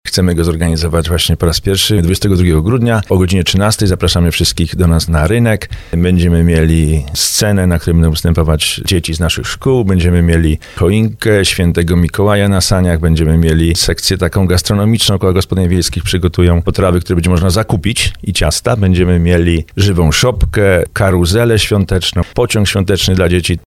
mówi burmistrz Tomasz Kijowski.